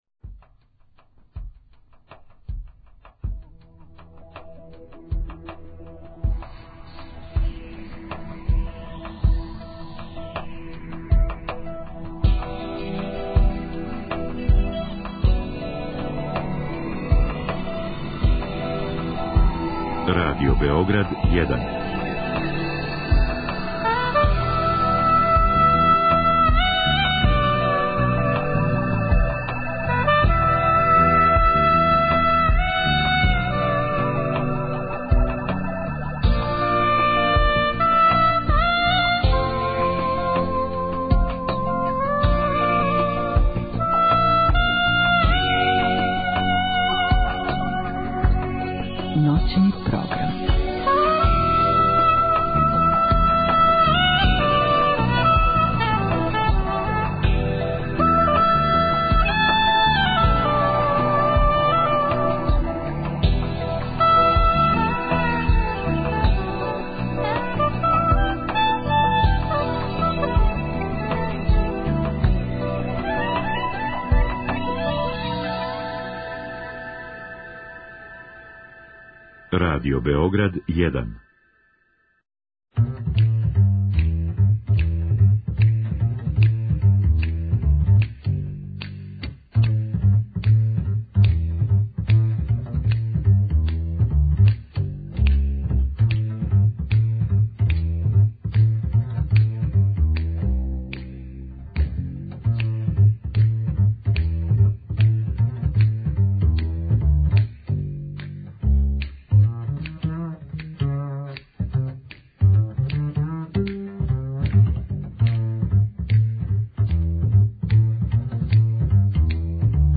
У наставку ноћног програма, све до 4 сата изјутра, најављиваћемо догађаје који нас очекују почетком априла и слушати прилоге снимљене на недавно одржаним музичким догађајима.